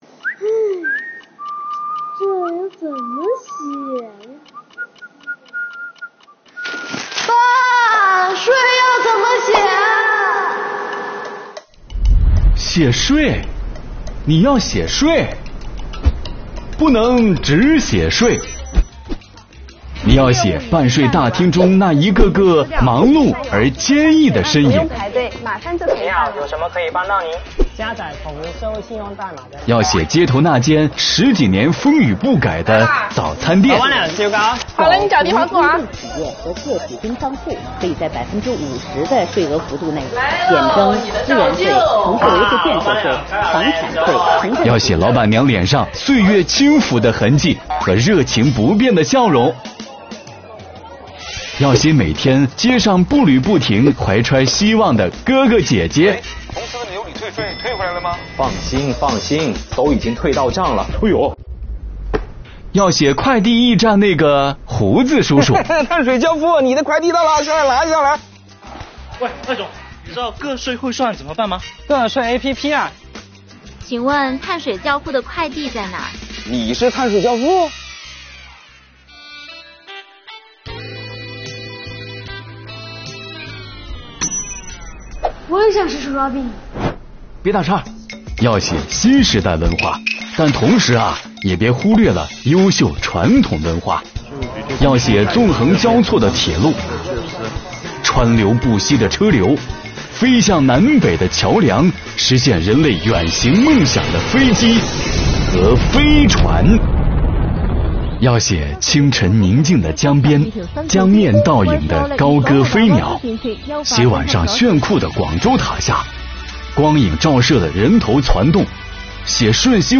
父亲辅导儿子写作文“我眼中的税”，父与子之间的对话引发了怎样的遐想空间呢？